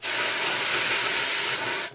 extinguisher.ogg